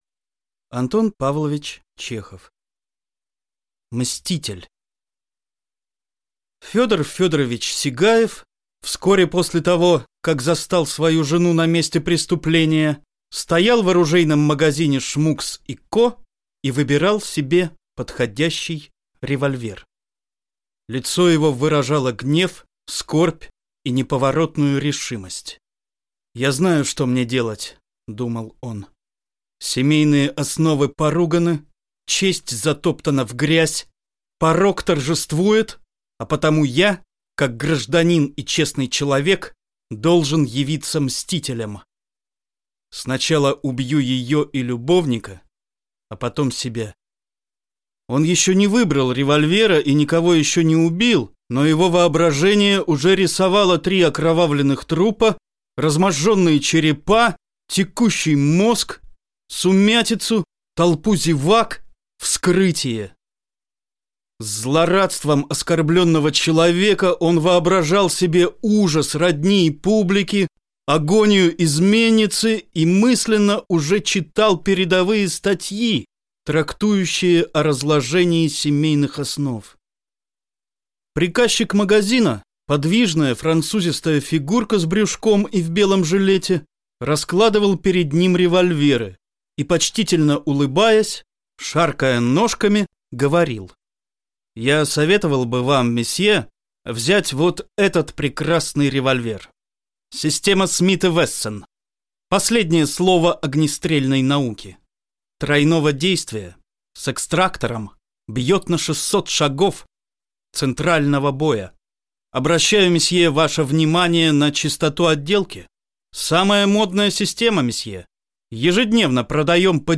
Аудиокнига Мститель | Библиотека аудиокниг